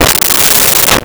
Glass Slide Only 03
Glass Slide Only 03.wav